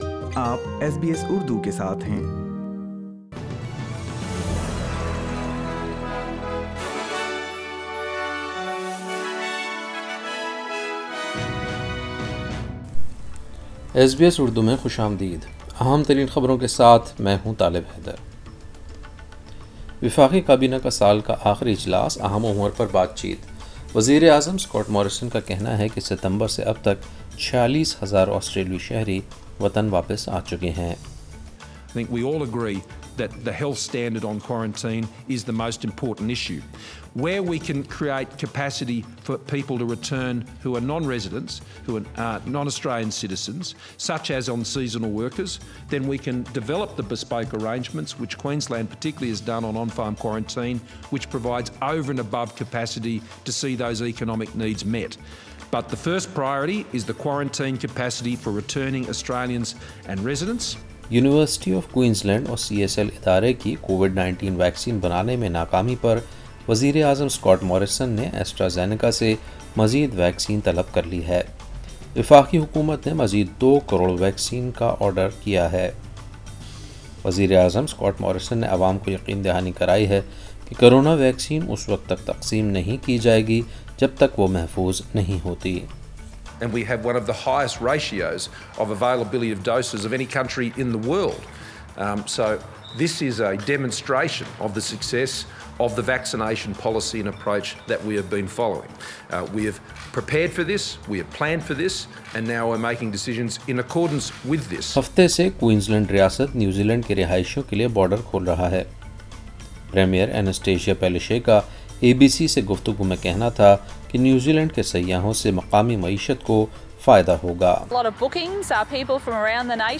ایس بی ایس اردو خبریں 11 دسمبر 2020